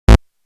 Chiptune-motion-sound-effect.mp3